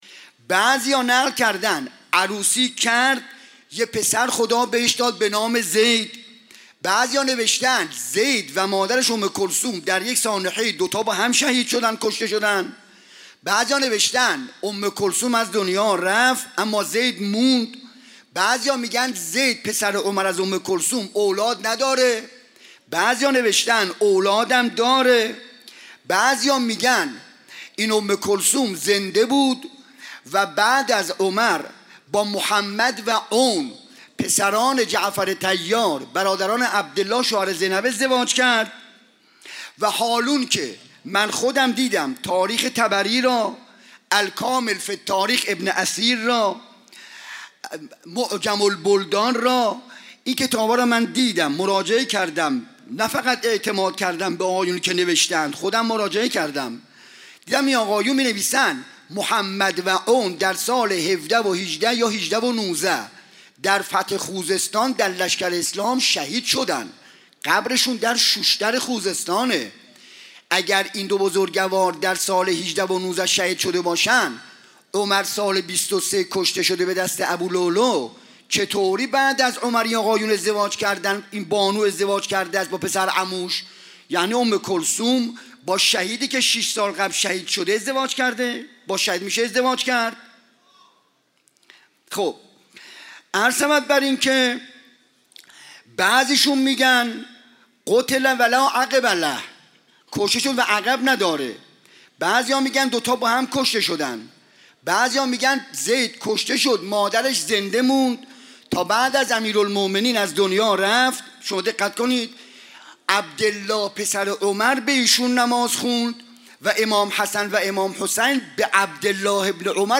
َشب سوم محرم 95_سخنراني_بخش سوم